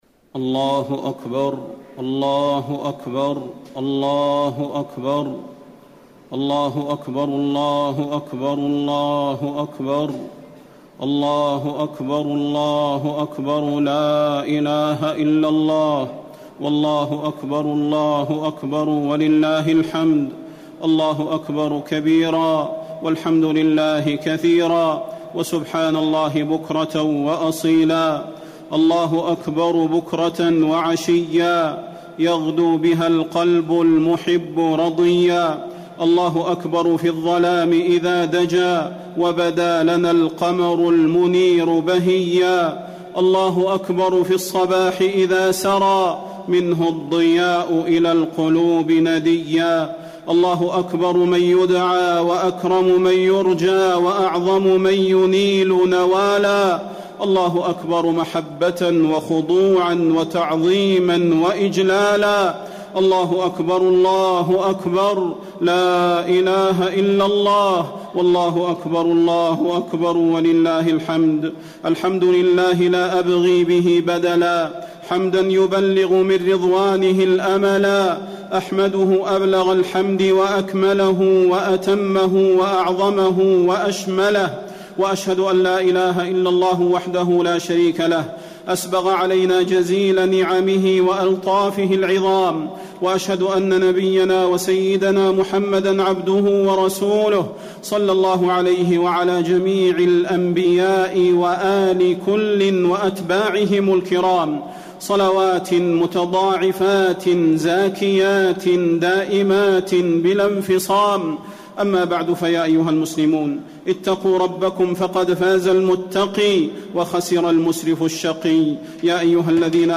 خطبة عيد الأضحى - المدينة - الشيخ صلاح البدير1439
تاريخ النشر ١٠ ذو الحجة ١٤٣٩ هـ المكان: المسجد النبوي الشيخ: فضيلة الشيخ د. صلاح بن محمد البدير فضيلة الشيخ د. صلاح بن محمد البدير خطبة عيد الأضحى - المدينة - الشيخ صلاح البدير1439